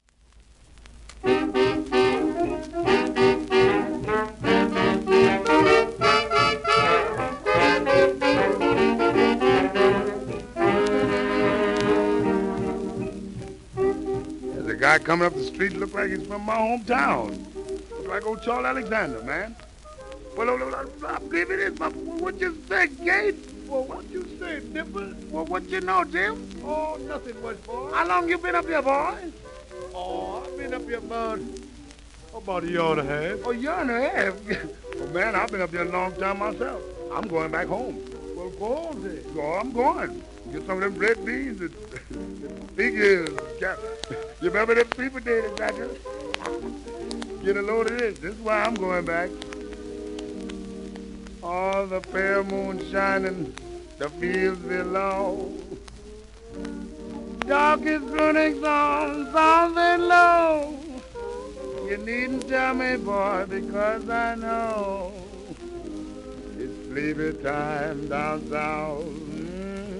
盤質A-/B+ *キズ(クリック音あり)、レーベルキズと退色
1931年録音